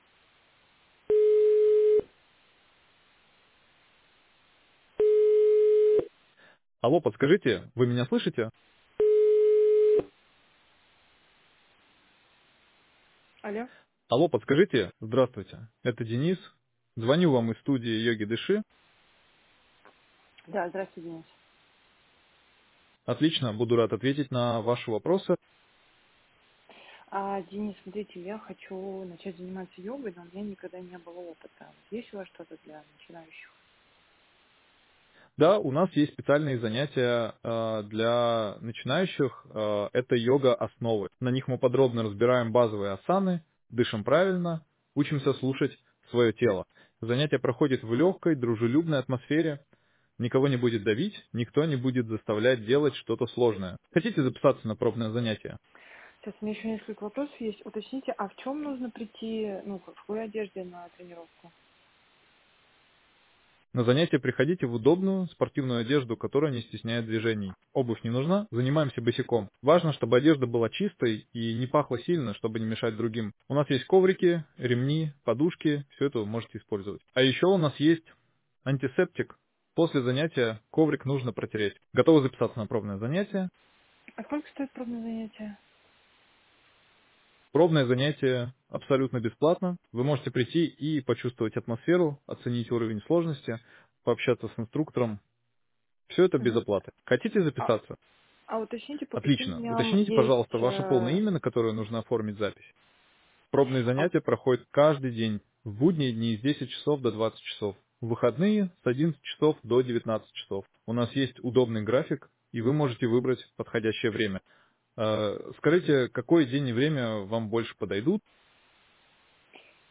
“AI-звонок”
• Очень реалистичный голос
голос Денис - йога клуб